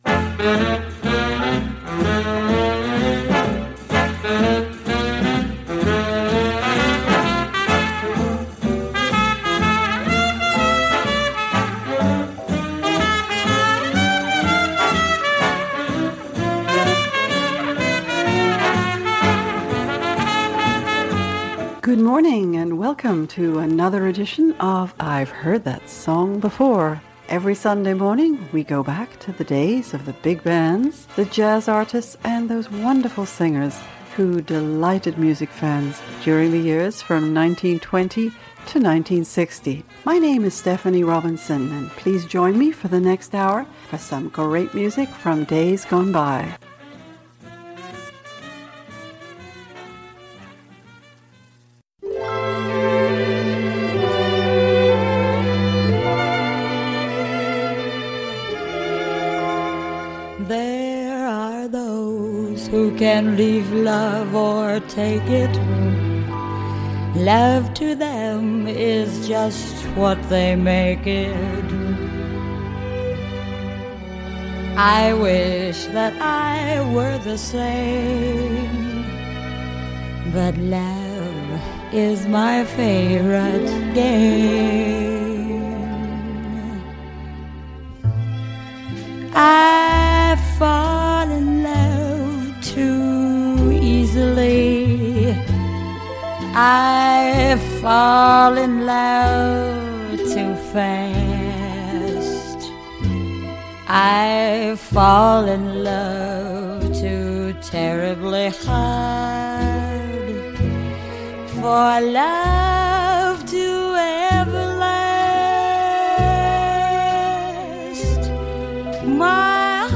interpretations of jazz and popular standards